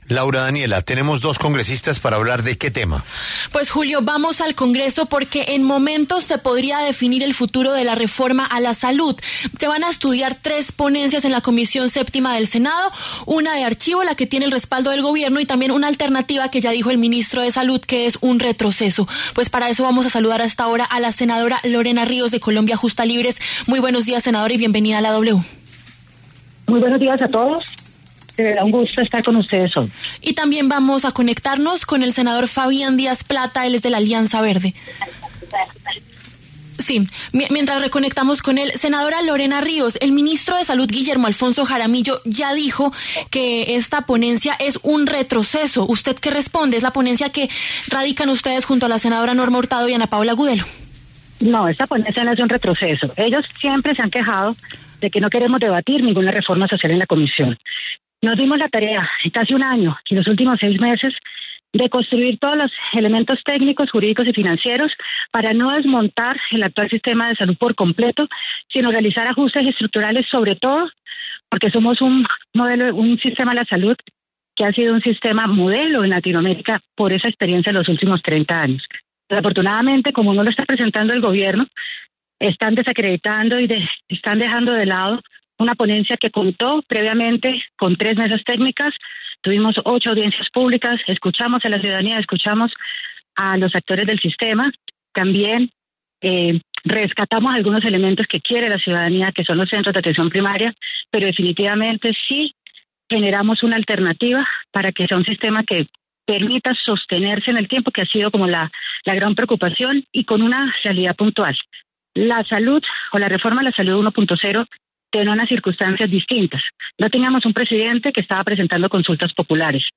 Debate: ¿Ponencia alternativa de reforma a la salud es un “retroceso”, como dice el MinSalud?